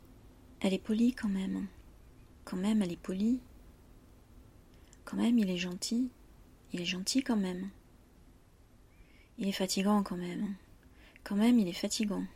Il arrive que dans une phrase, en fonction du ton employé, il y ait une nuance différente. Il y a une très légère pause entre quand même et le reste de la phrase.